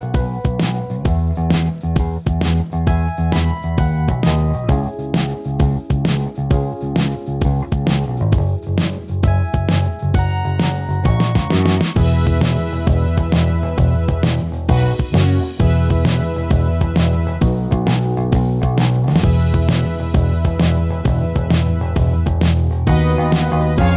Minus Guitars Rock 4:22 Buy £1.50